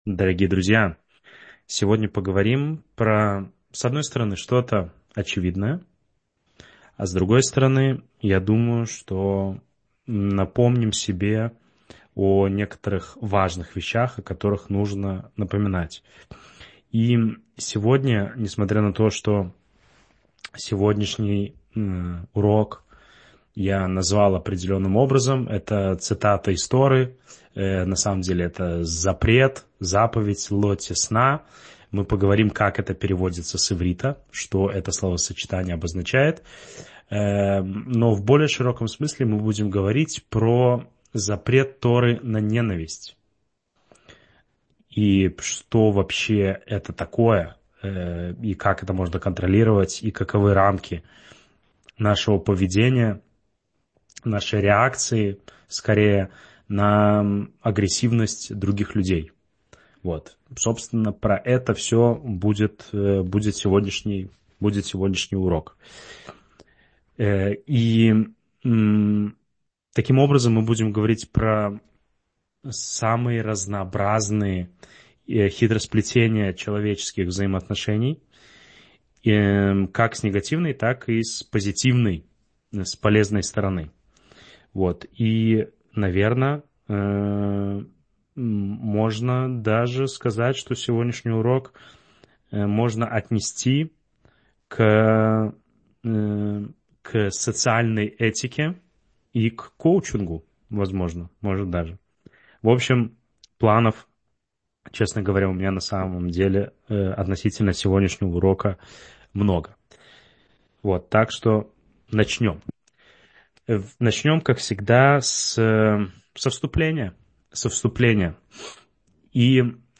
— слушать лекции раввинов онлайн | Еврейские аудиоуроки по теме «Еврейские законы» на Толдот.ру